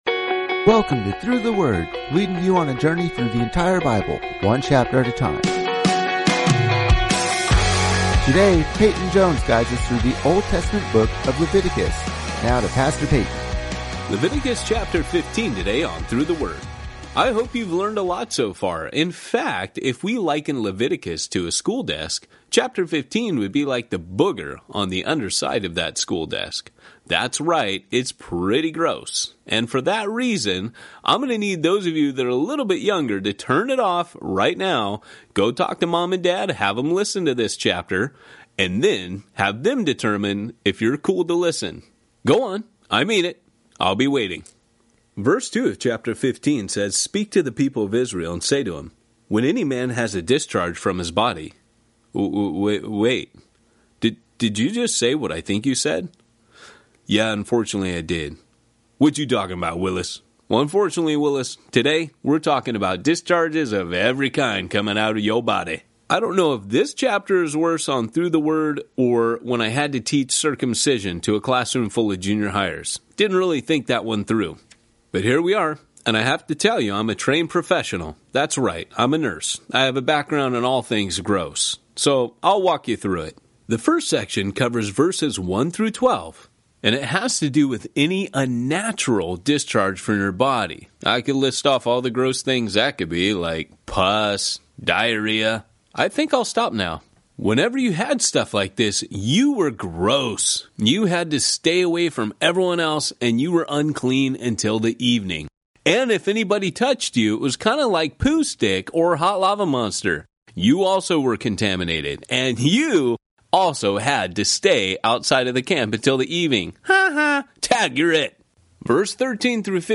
19 Journeys is a daily audio guide to the entire Bible, one chapter at a time. Each journey takes you on an epic adventure through several Bible books as your favorite pastors explain each chapter in under ten minutes.